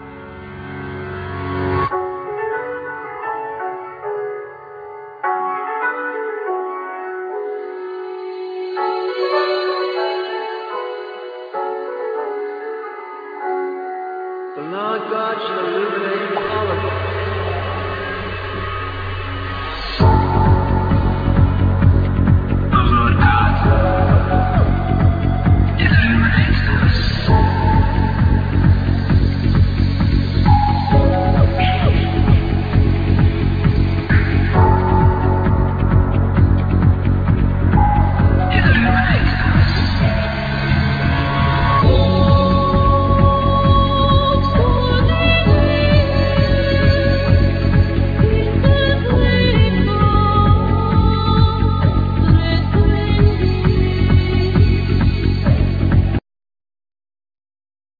Piano, Keyboards
Voice
Cello
Rhythm programming, Sample, Loops
Live erectronics